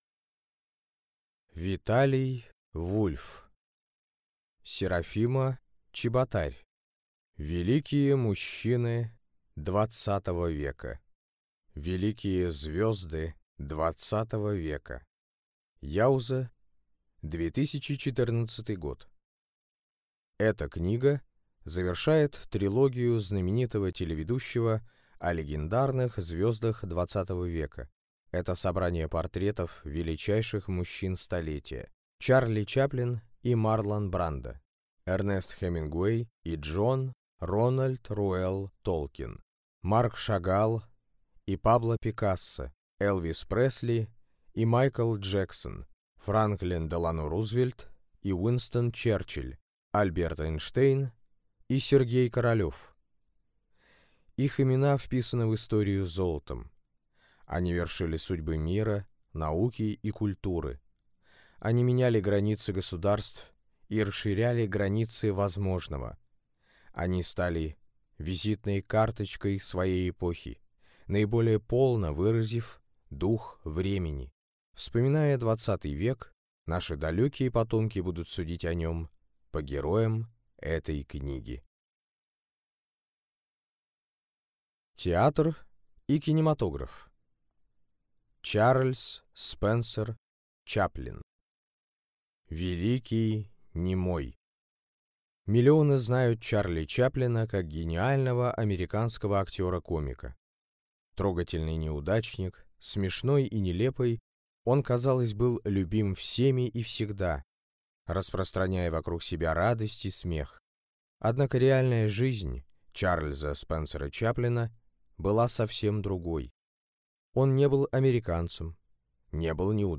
Аудиокнига Великие мужчины XX века | Библиотека аудиокниг